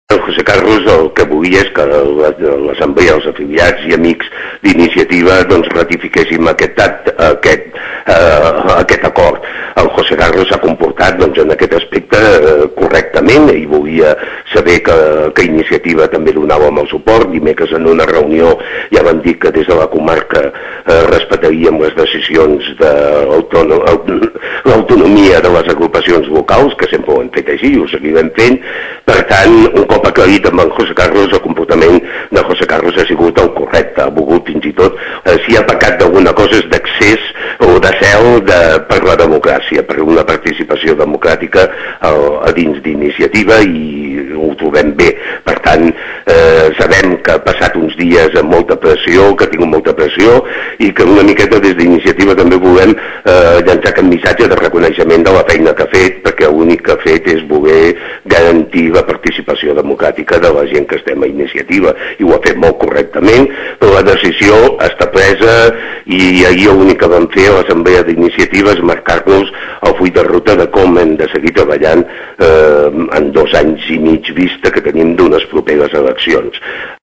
en una entrevista als serveis informatius d’aquesta emissora